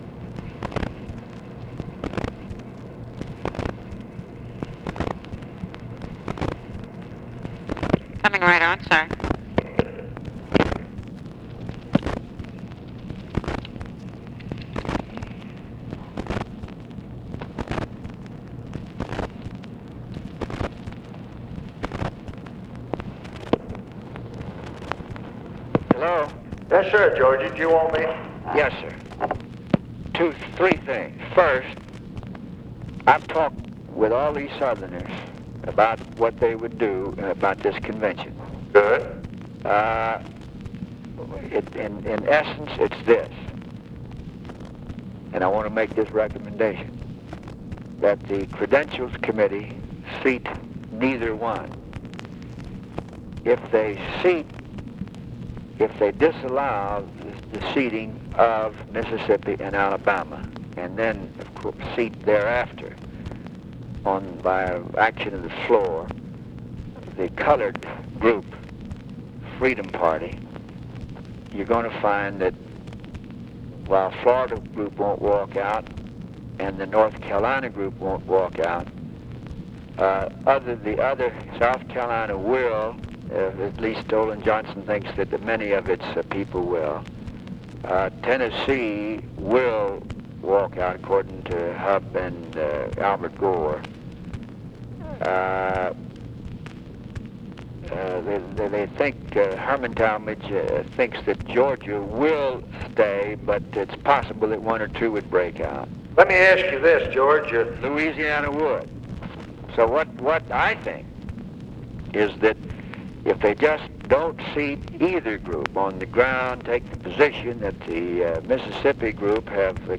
Conversation with GEORGE SMATHERS, August 20, 1964
Secret White House Tapes